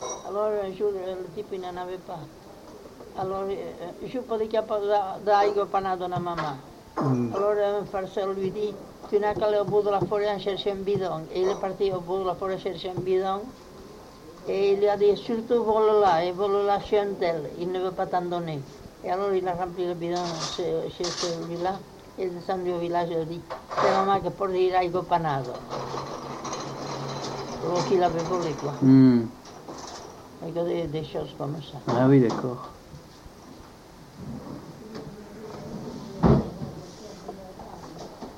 Aire culturelle : Couserans
Lieu : Castillon-en-Couserans
Genre : conte-légende-récit
Type de voix : voix de femme
Production du son : parlé